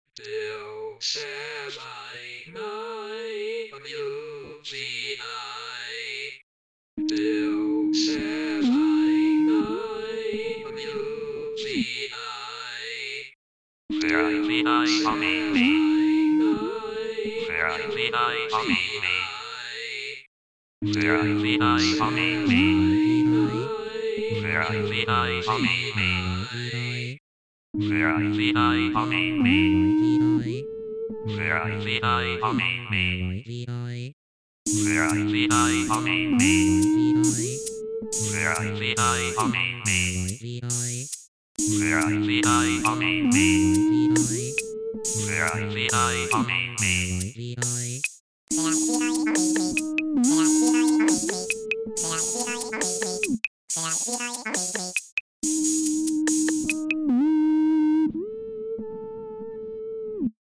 Vocals, Art Direction, Band
Synthesizer, L.S.I., Band
Keyboards, Uillean Pipes, Band